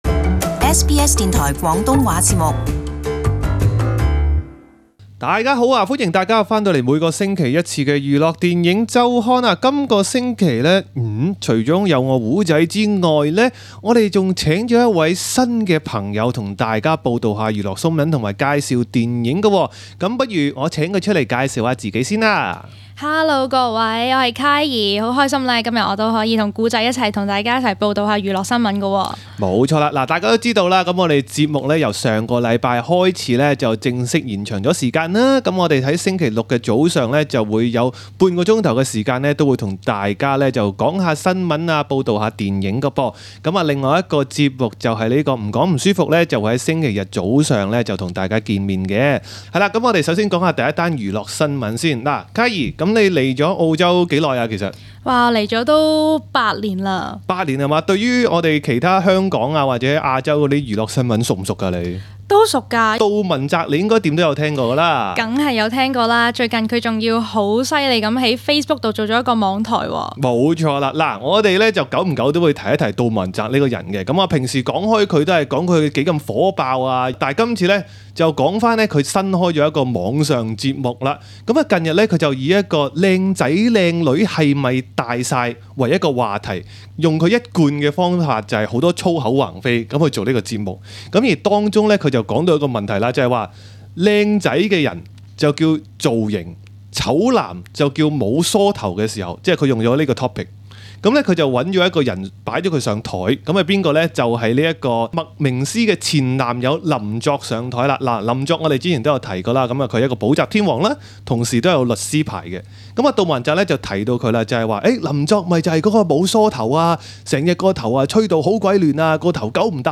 在今个星期的『年青人时间』 内，两位节目主持人会为大家带来多则热辣辣娱乐新闻。